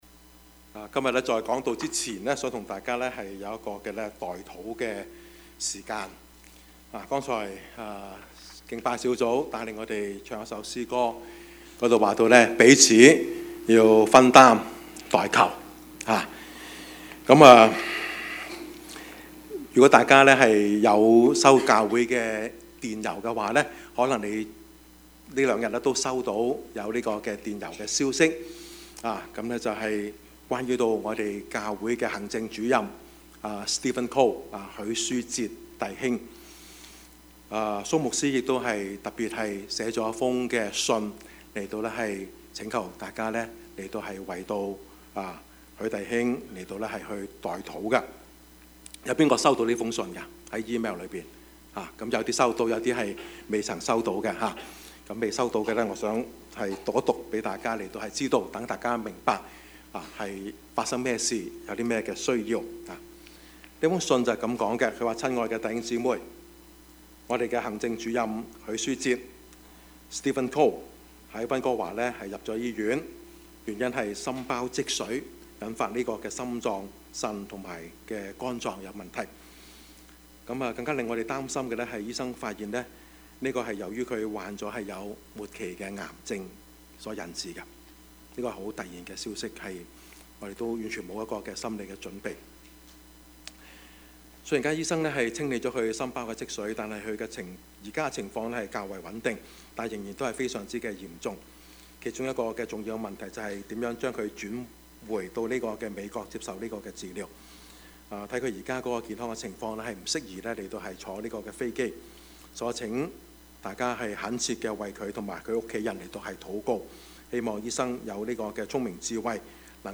Service Type: 主日崇拜
Topics: 主日證道 « 日光下的方舟 義和團與中國內地會 »